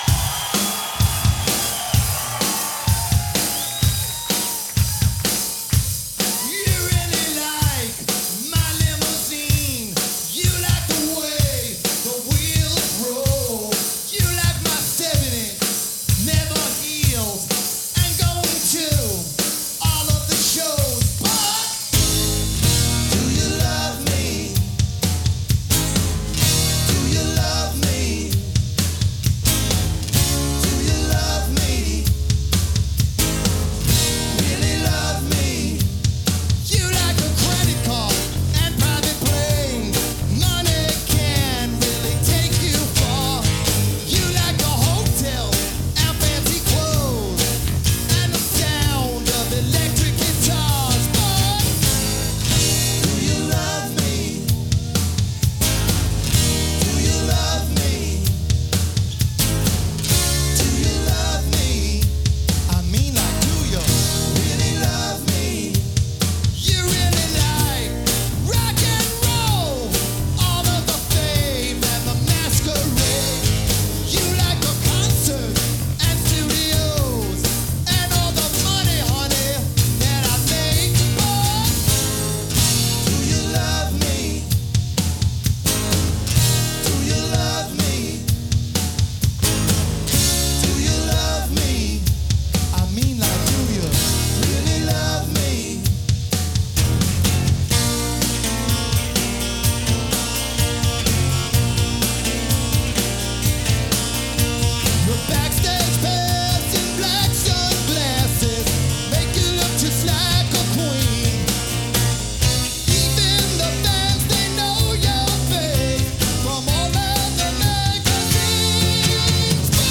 类型：Hard Rock